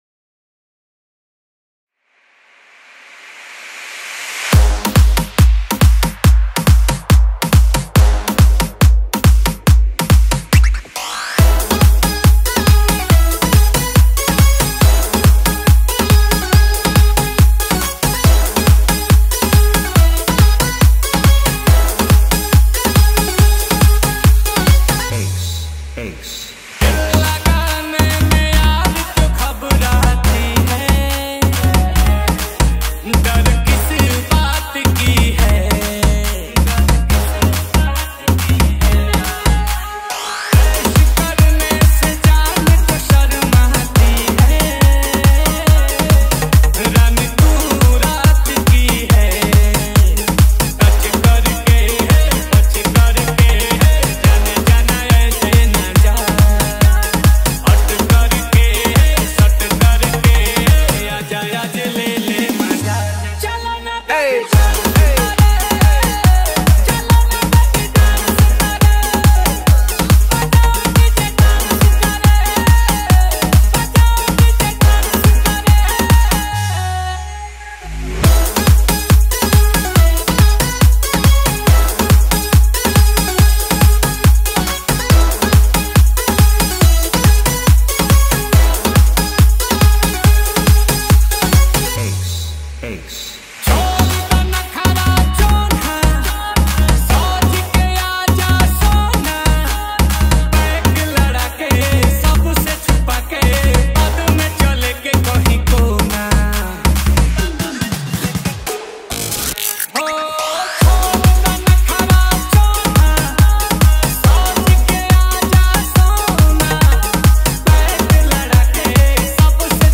Edm Humming Bass Mix